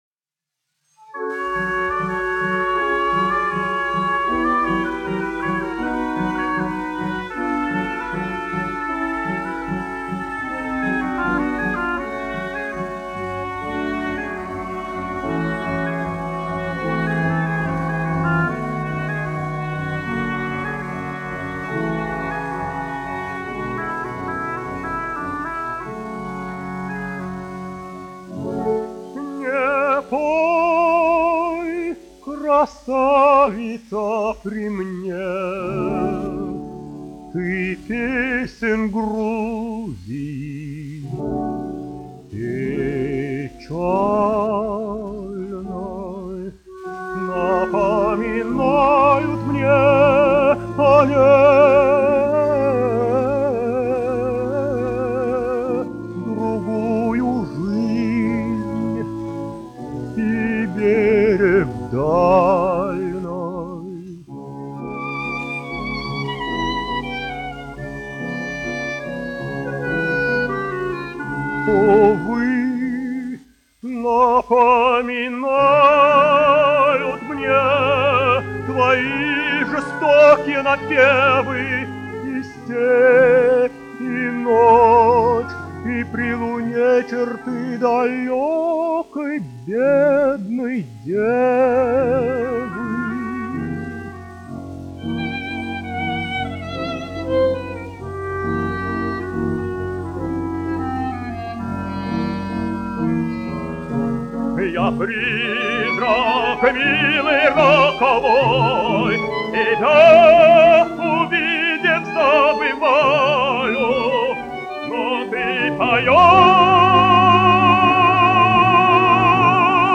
1 skpl. : analogs, 78 apgr/min, mono ; 25 cm
Romances (mūzika)
Dziesmas (vidēja balss)
Skaņuplate